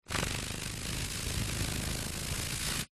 Звуки жуков
На этой странице собраны разнообразные звуки жуков: стрекот, жужжание, шелест крыльев.
Шепот моли, мелькнувшей в темноте